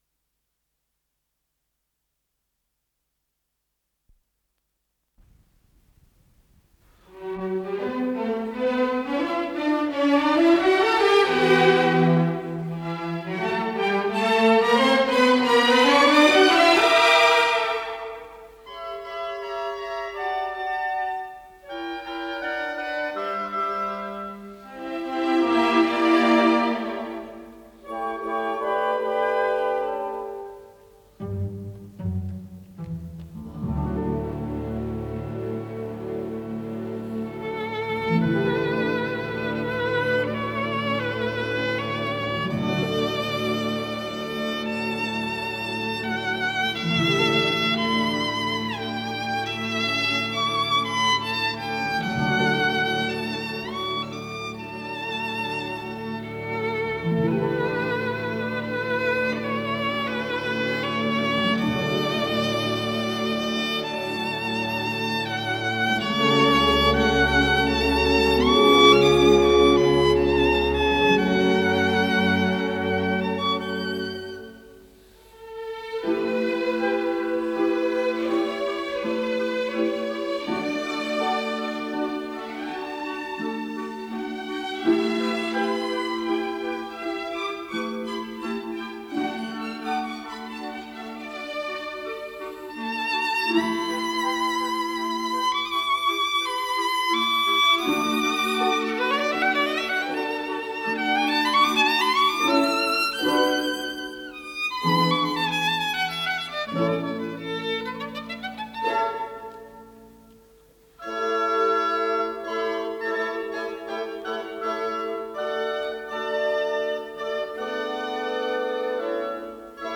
с профессиональной магнитной ленты
ИсполнителиБольшой симфонический оркестр Всесоюзного радио и Центрального телевидения
Соло на скрипке
Художественный руководитель и дирижёр - Геннадий Рождественский
ВариантДубль моно